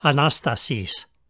ἀνάστασις / anastasis
These two forms are the same letter and pronounced the same.